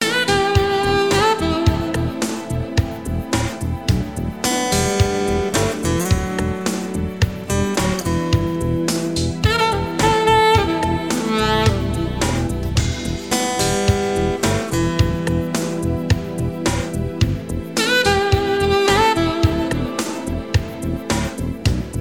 Знаменитые мелодии в исполнении саксофона